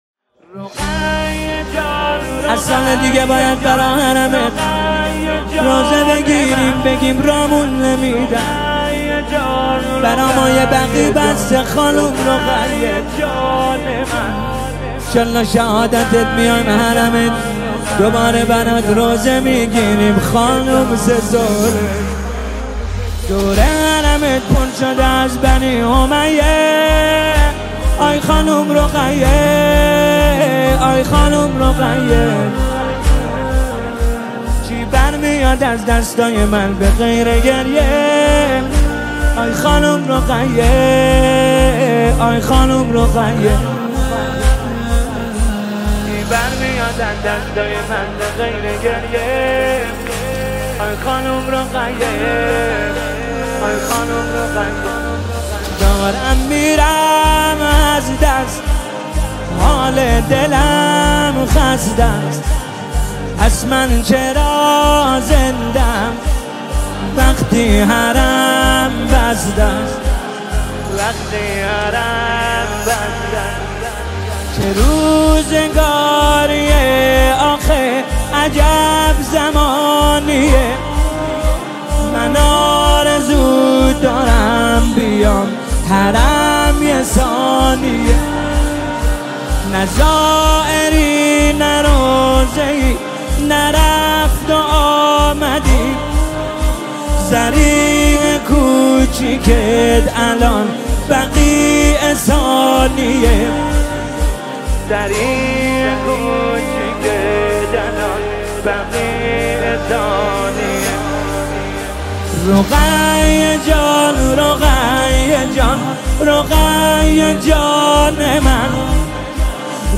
مداحی شهادت حضرت رقیه